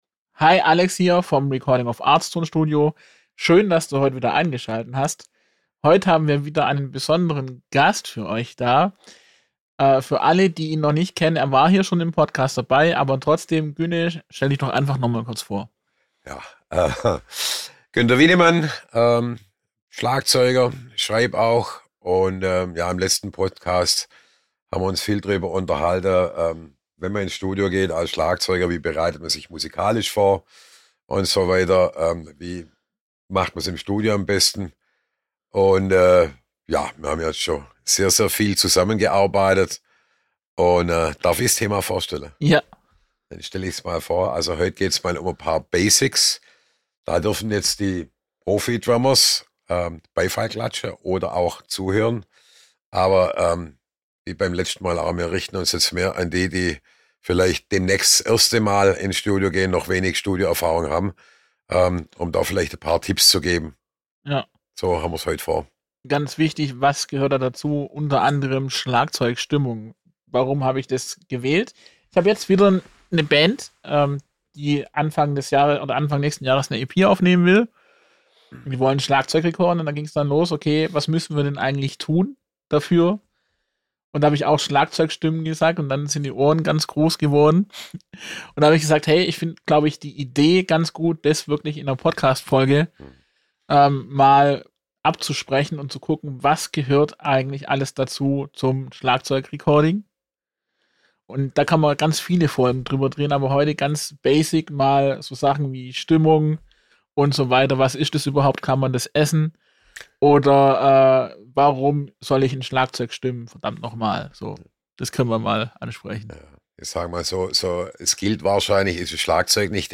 Ein ehrliches Gespräch über Handwerk, Vorbereitung und den Unterschied zwischen „irgendwie aufnehmen“ und wirklich Musik produzieren.